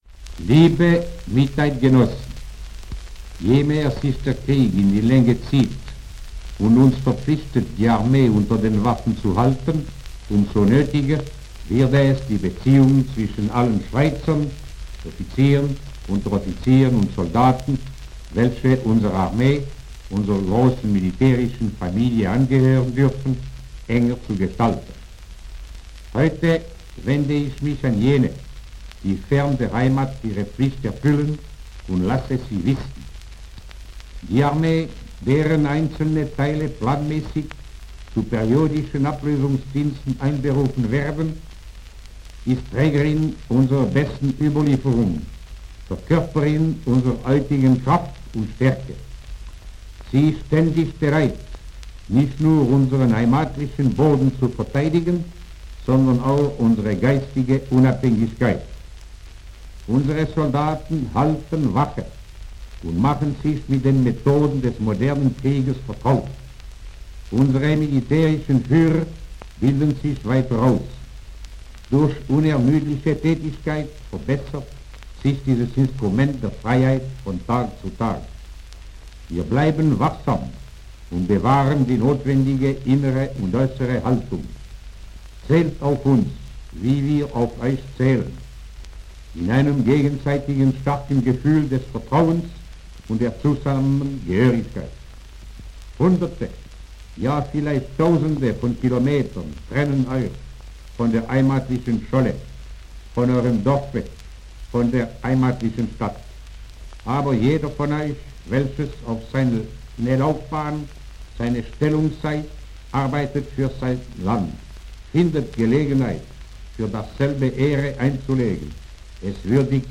Ansprache General Guisan 1942: Wir vergessen Euch nicht!
In dieser Aufnahme vom April 1942 wandte sich General Henri Guisan auf Deutsch speziell an die Schweizerinnen und Schweizer im Ausland.